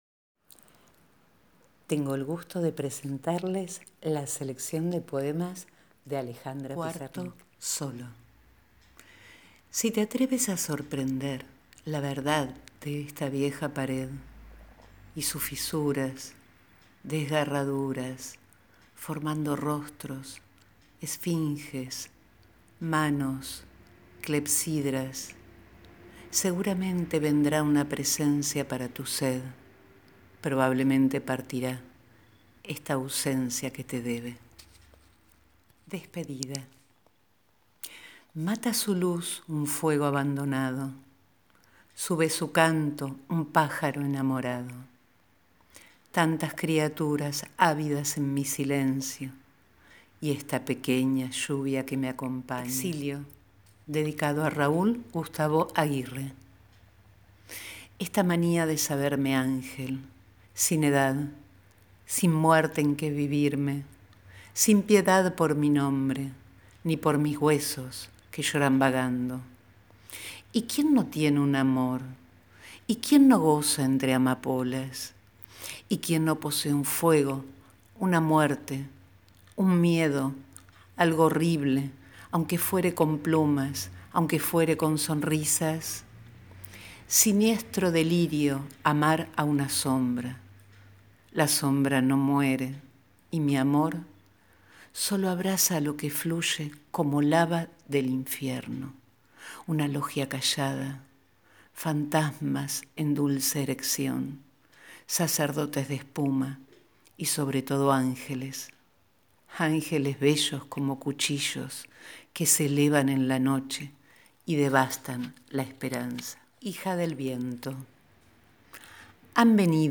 Esta vez elegimos una selección de poemas de Alejandra Pizarnik (1936-1972).
Ahora solo la voz y la escucha, para que decante el poema.